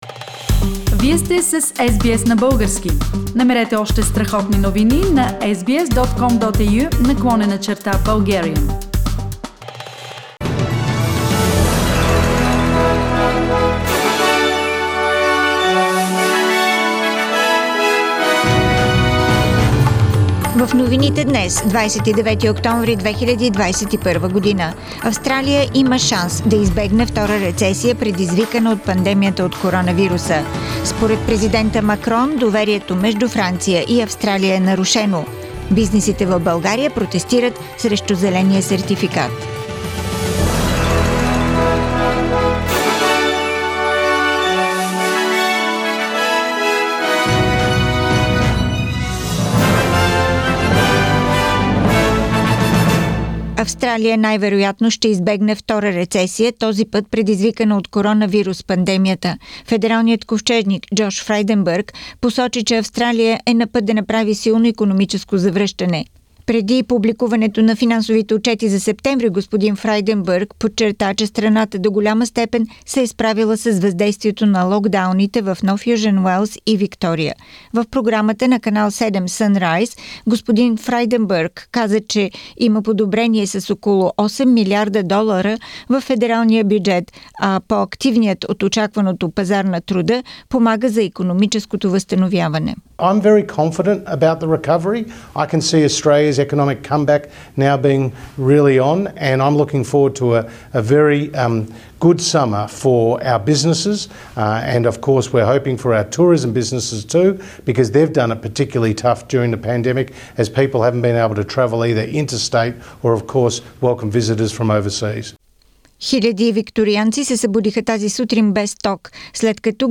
Weekly Bulgarian News – 29th October 2021